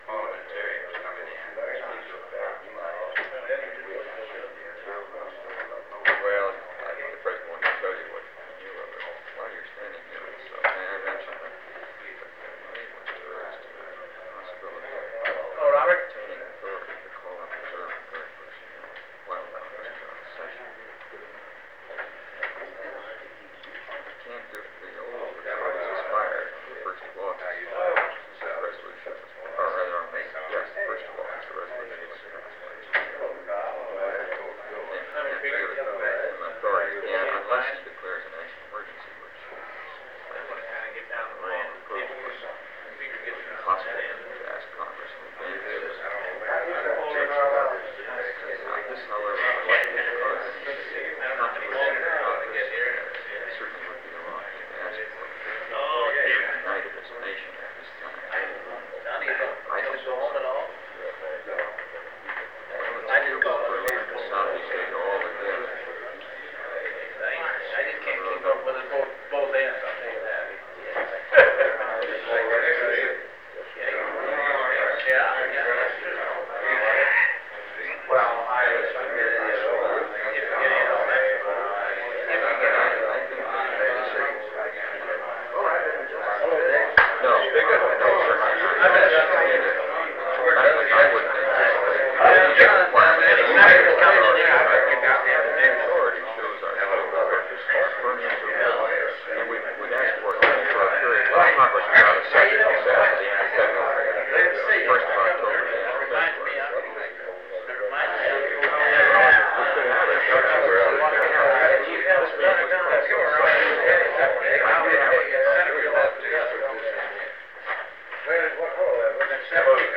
Meeting with Congressional Leadership on Cuba
Secret White House Tapes | John F. Kennedy Presidency Meeting with Congressional Leadership on Cuba Rewind 10 seconds Play/Pause Fast-forward 10 seconds 0:00 Download audio Previous Meetings: Tape 121/A57.